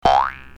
clock03.ogg